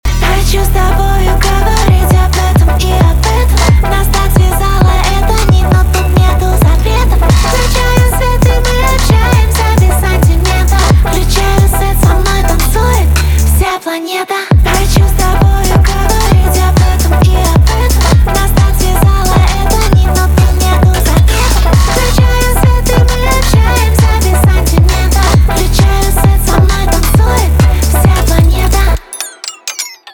поп
нарастающие , битовые , басы , танцевальные , качающие